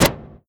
EXPLOSION_Arcade_02_mono.wav